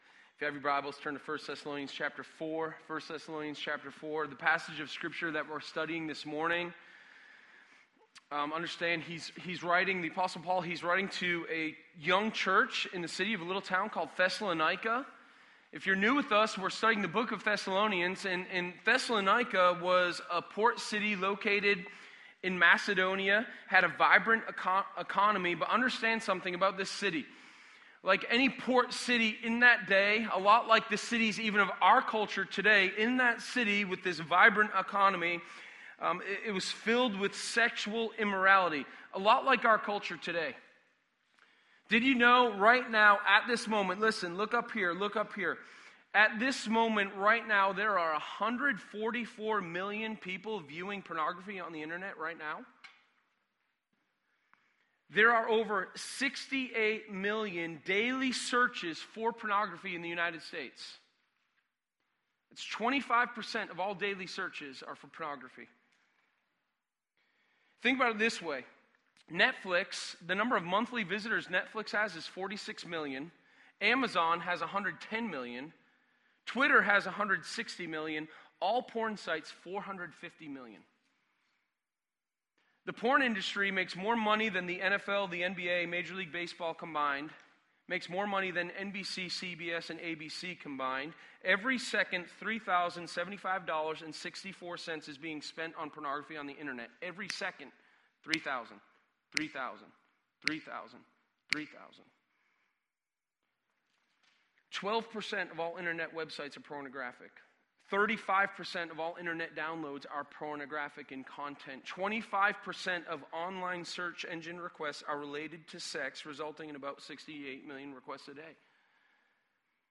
Sermon1204_7MakingPurityaPriority.mp3